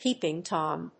アクセント・音節Péep・ing Tóm